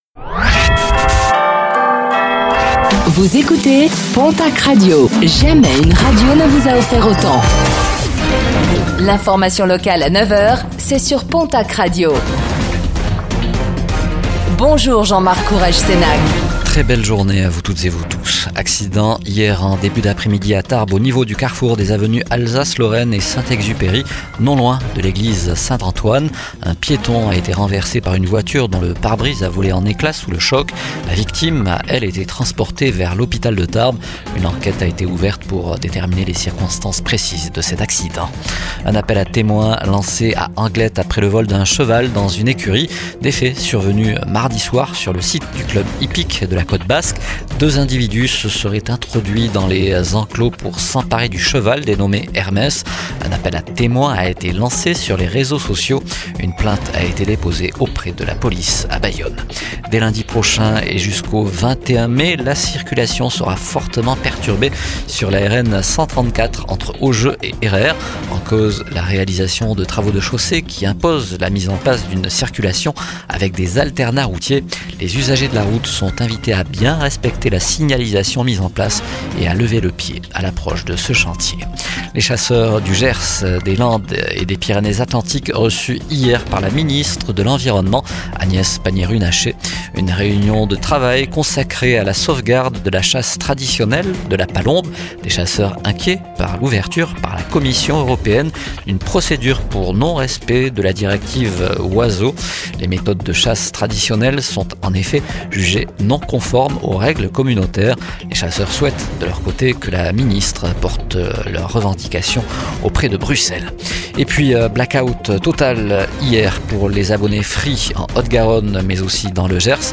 Réécoutez le flash d'information locale de ce jeudi 08 mai 2025